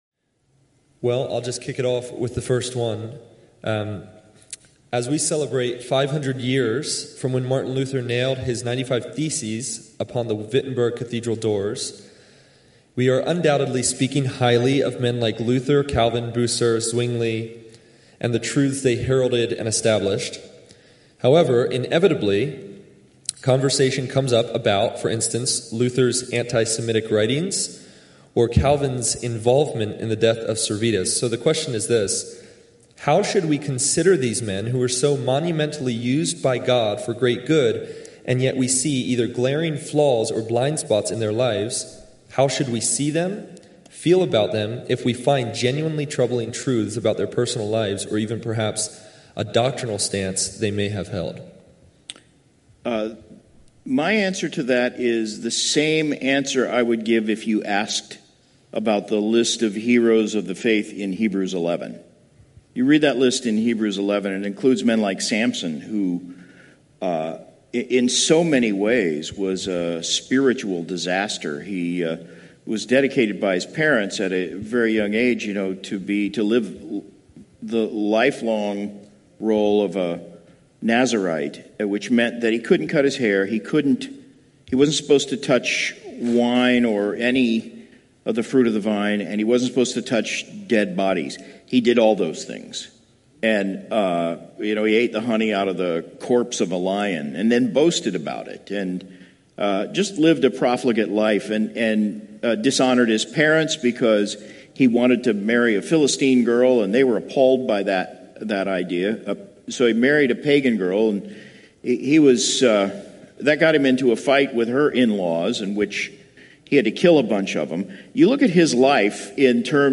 From the: The Pillars of the Reformation Conference || GCC Laredo || Nov. 10-12, 2017